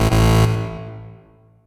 finesseError_long.ogg